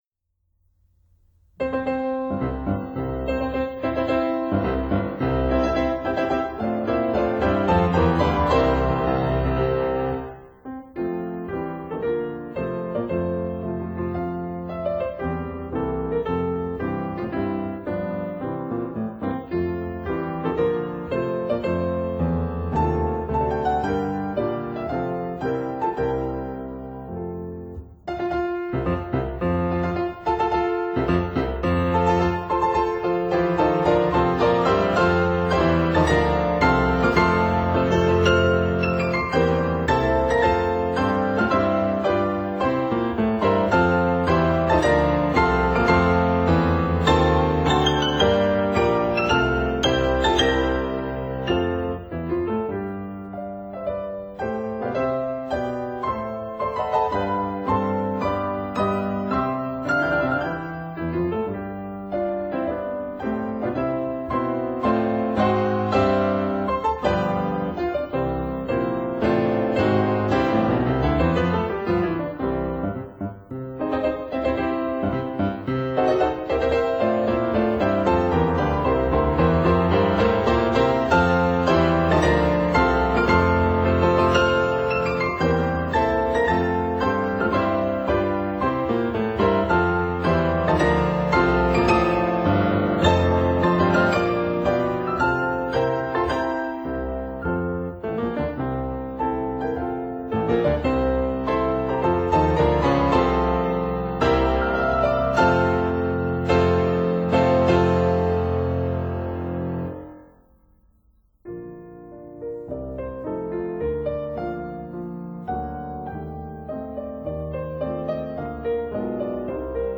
piano duo